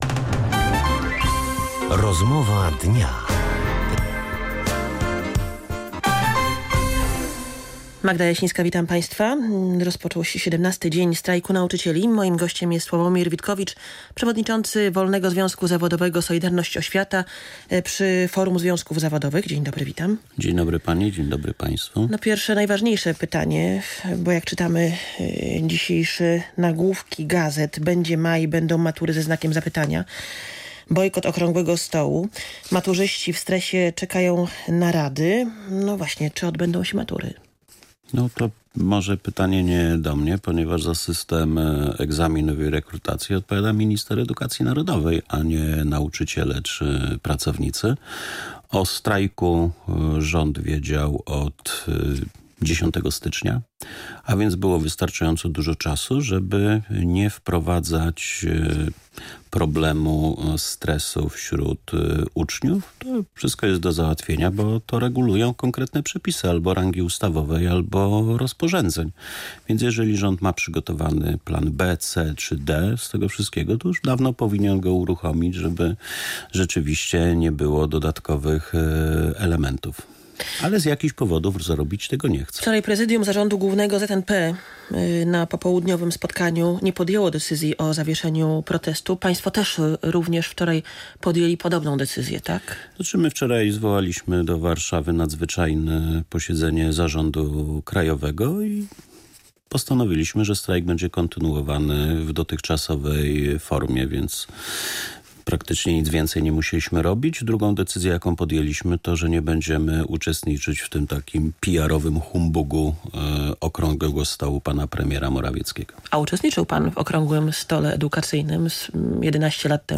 Rozmowa dnia w Radiu Pik